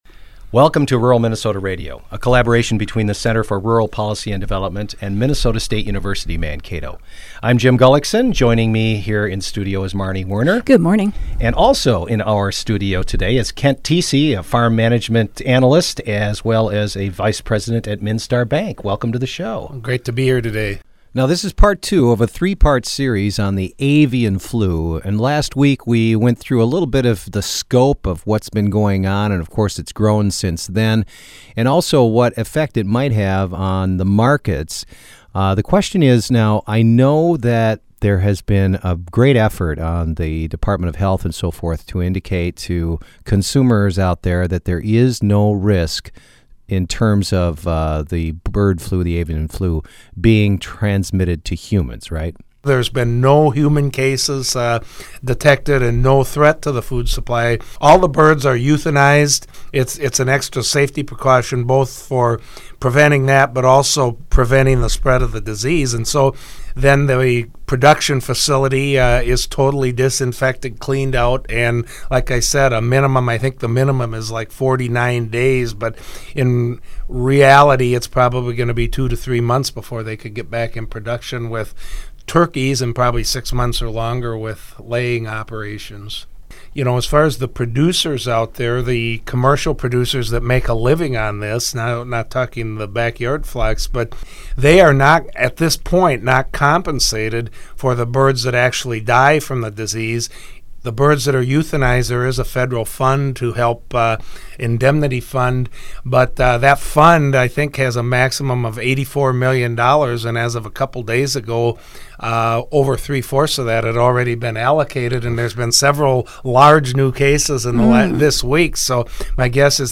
This week on Rural MN Radio, we’re continuing our conversation